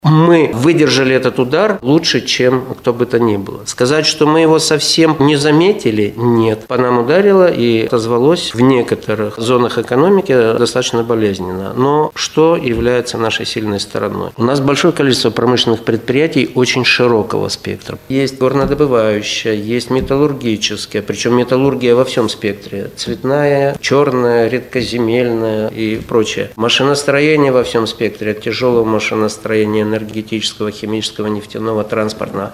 на пресс-конференции ТАСС-Урал. По его словам, сохранить региональную экономику помогает промышленная сфера, которая хорошо развита на Среднем Урале.